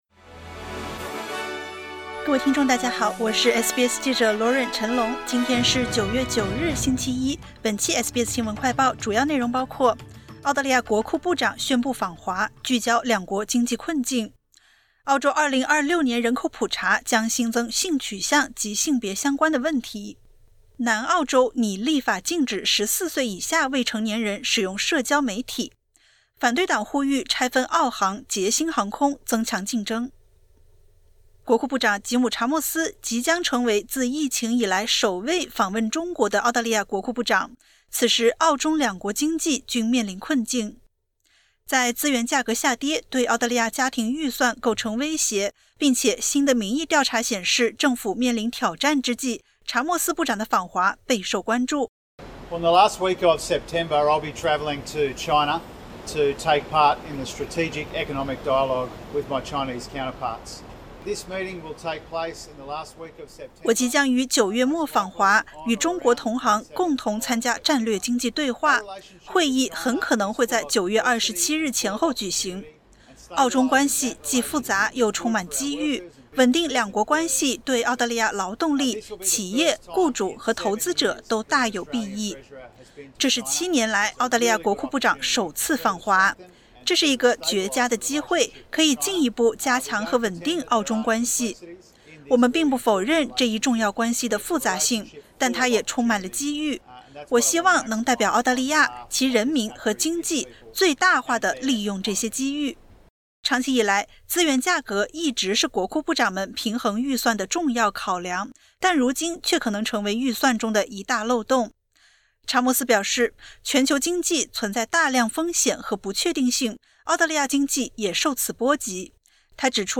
【SBS新闻快报】澳国库部长宣布访华 聚焦两国经济困境